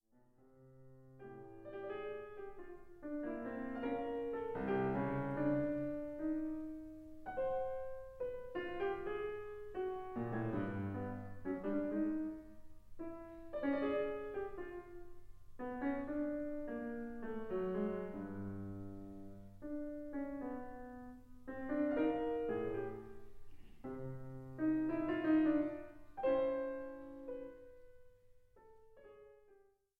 アコースティック
アバンギャルド
インストゥルメンタル
録音・編集も、ライブの緊迫した臨場感を伝えつつ、クオリティの高い仕上がり。
ピアノソロ